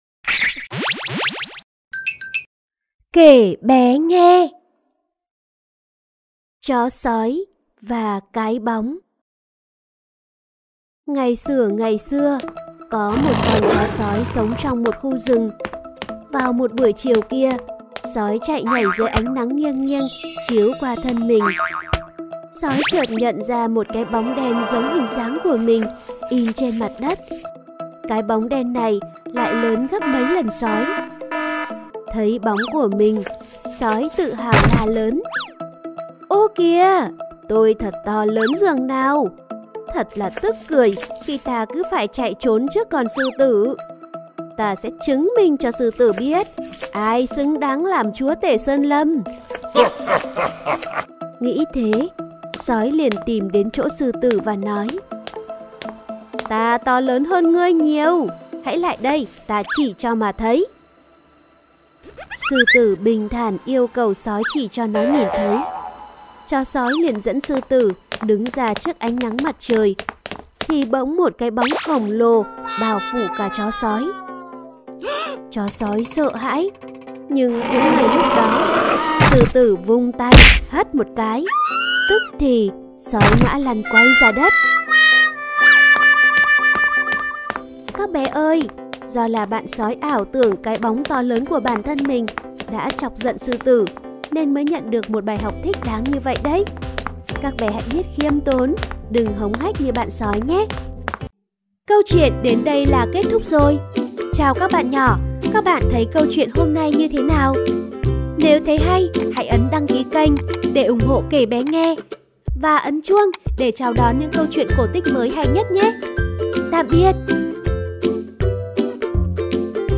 Sách nói | Chó Sói Và Cái Bóng